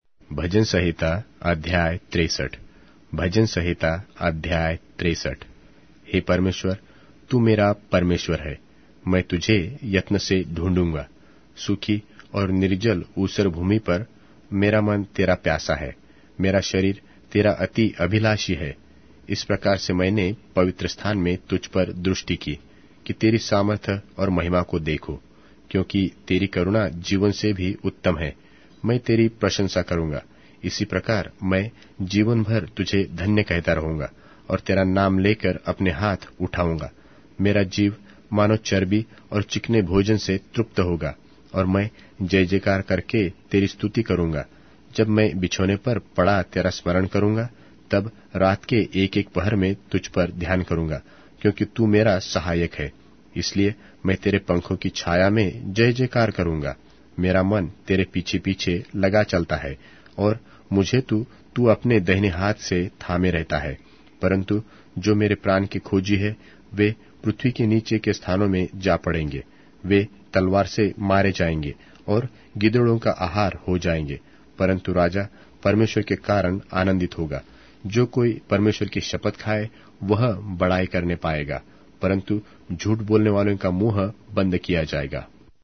Hindi Audio Bible - Psalms 148 in Orv bible version